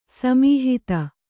♪ samīhita